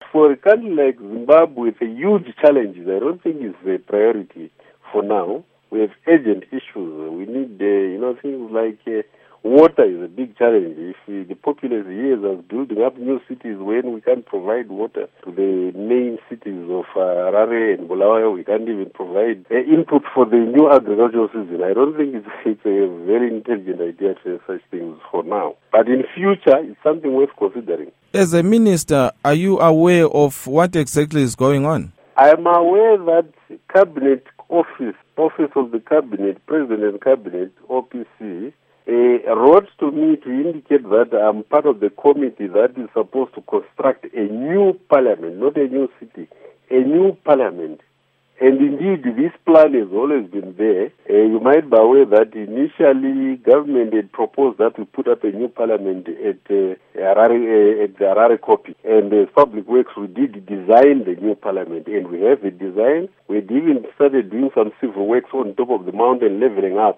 Interview With Joel Gabhuza